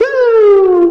报警1
描述：合成的警报
标签： 警笛声 刺耳 合成 电子 报警
声道立体声